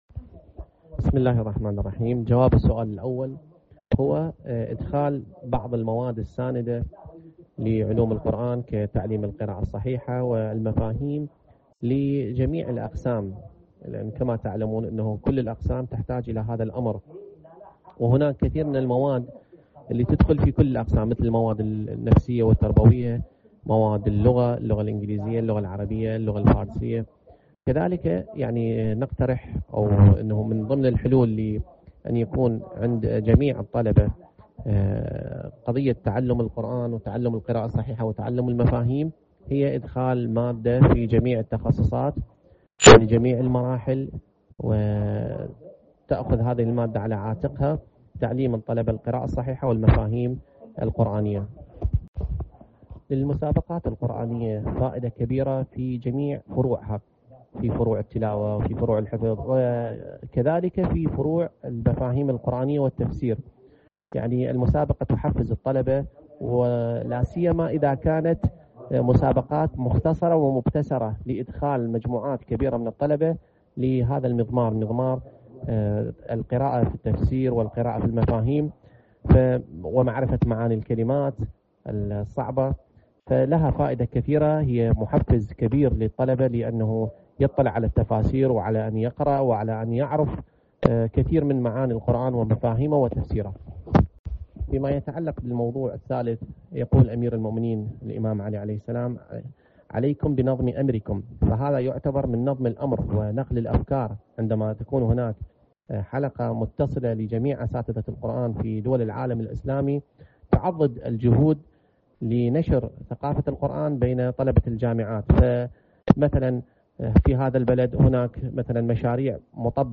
أكاديمي عراقي في حديث لـ"إکنا":